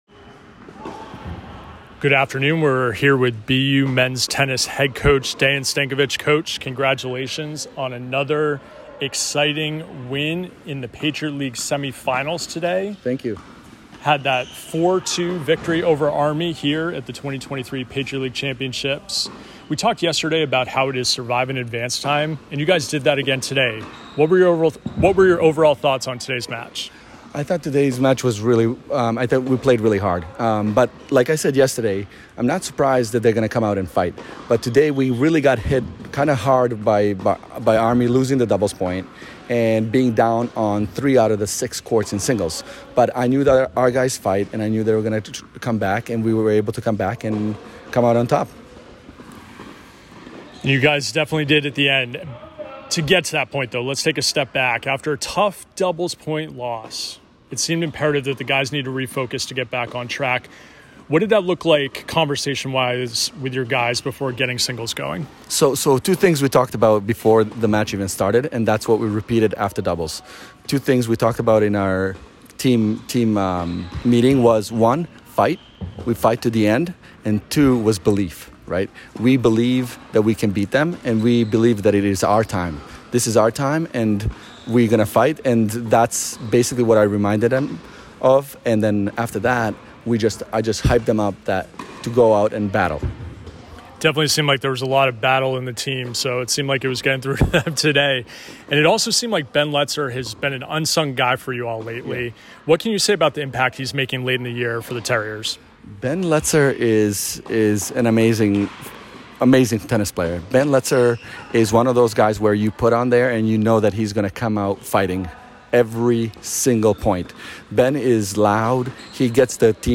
Army PL Semifinals Postmatch Interview